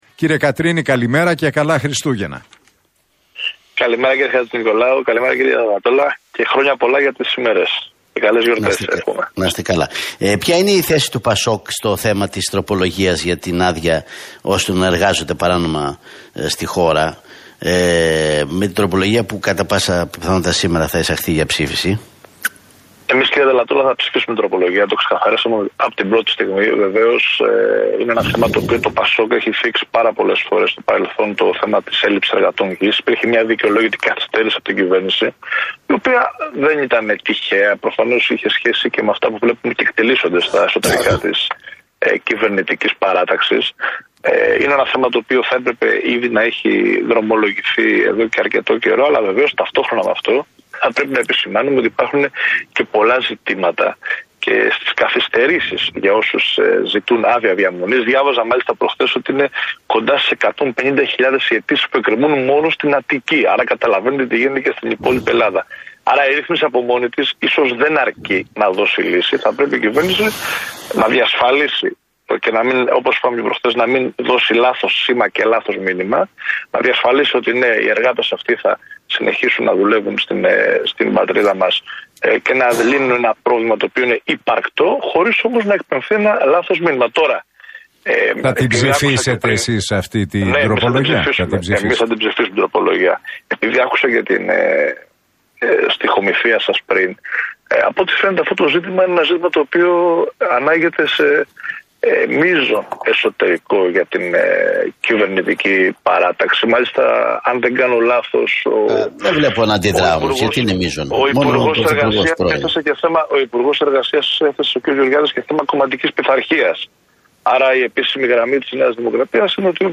Κατρίνης στον Realfm 97,8: Εμείς θα ψηφίσουμε την τροπολογία για τους μετανάστες